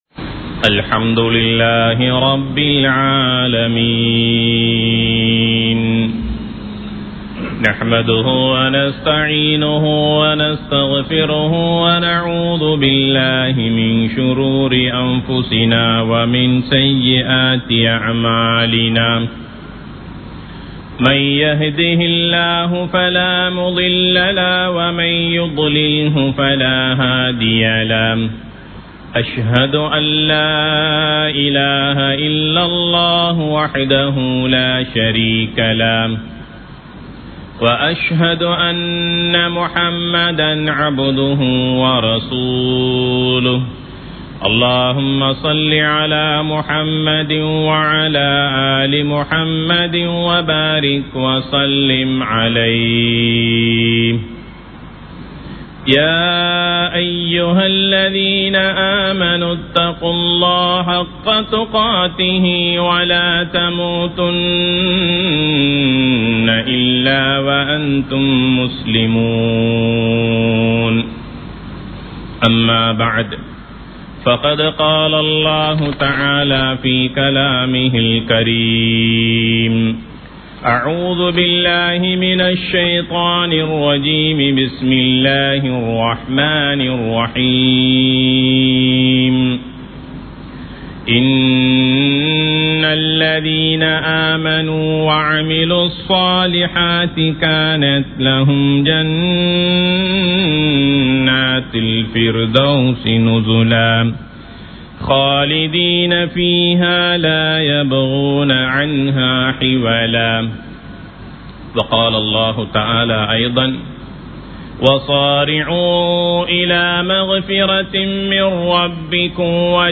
ரமழான் தந்த பாடம் | Audio Bayans | All Ceylon Muslim Youth Community | Addalaichenai
Kollupitty Jumua Masjith